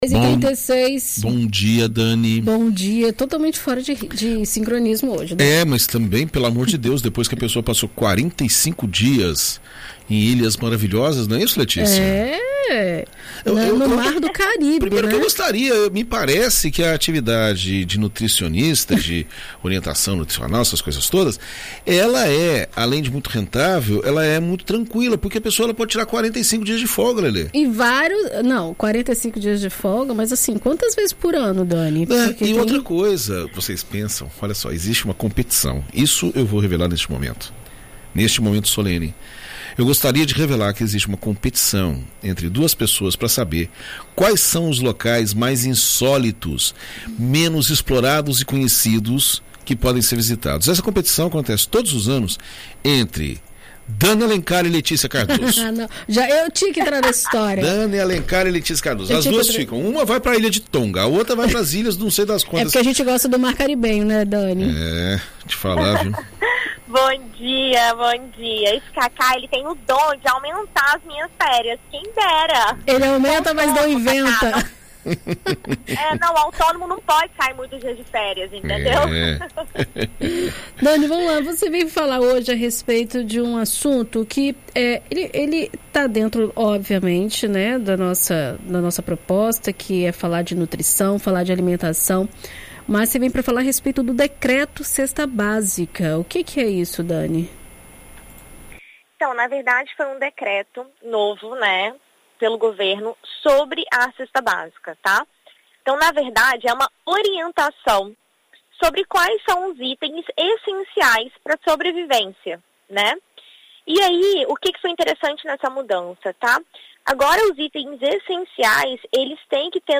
A nutricionista